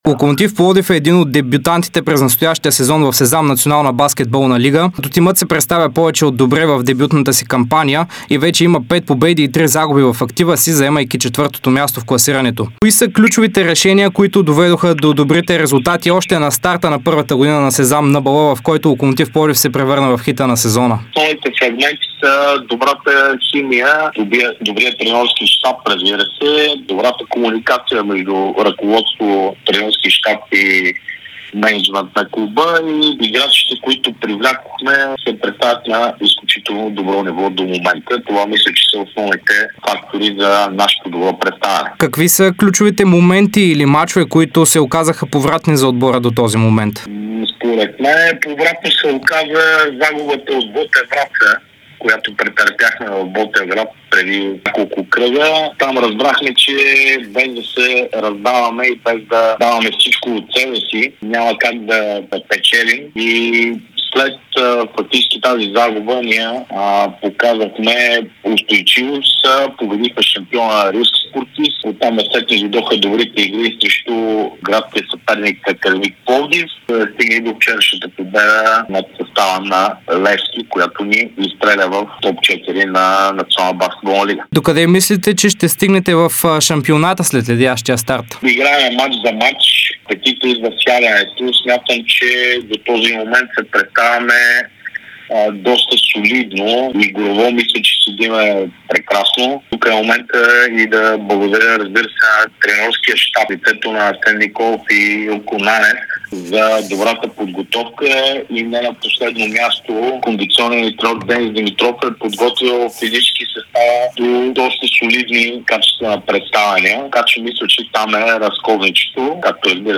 даде ексклузивно интервю пред Дарик и dsport